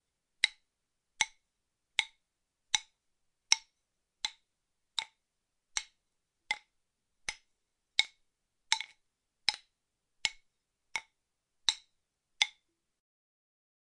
鼓棒 - 声音 - 淘声网 - 免费音效素材资源|视频游戏配乐下载
在墙上打一些鼓槌